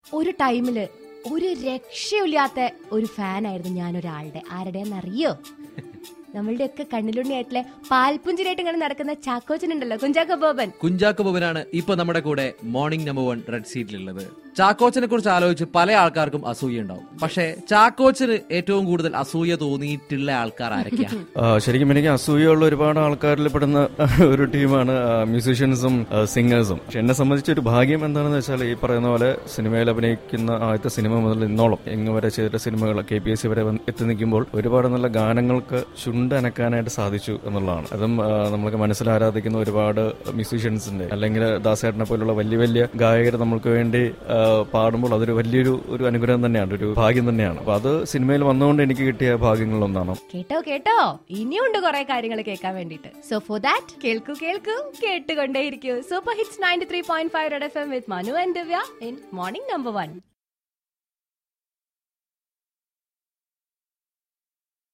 INTERVIEW WITH KUNCHAKO BOBAN.